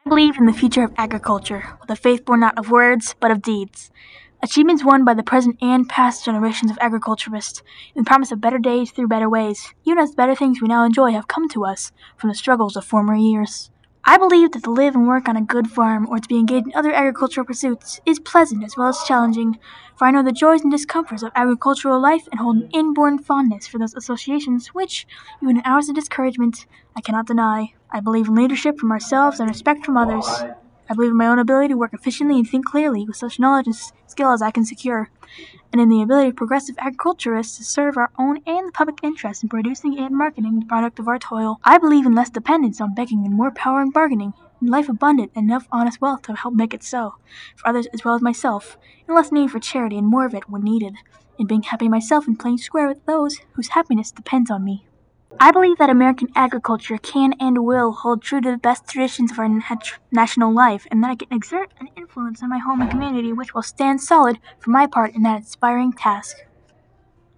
Here’s just me saying the creed.